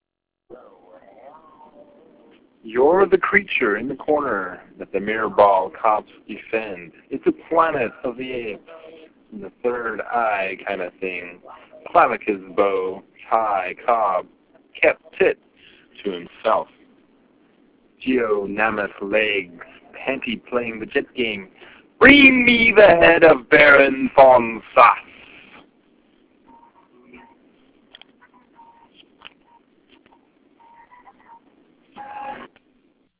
Creator's Tags: gridpoem reading